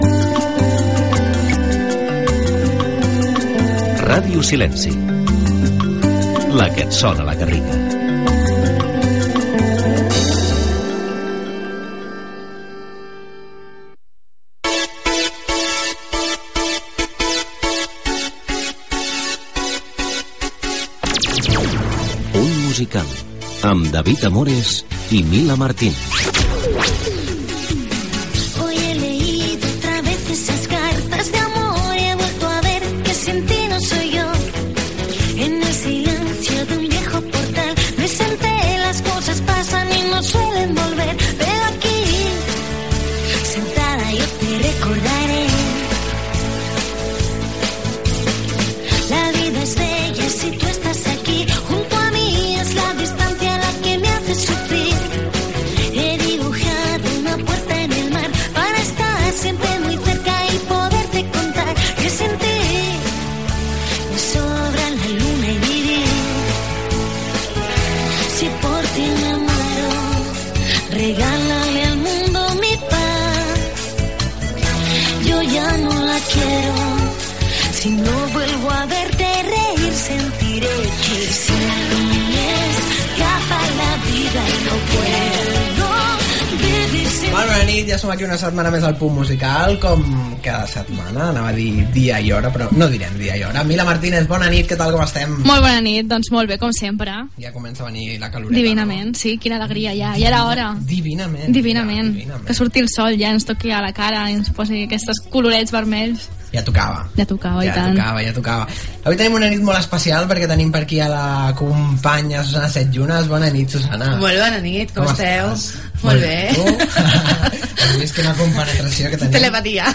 Indicatiu de la ràdio, presentació, temes musicals
Gènere radiofònic Musical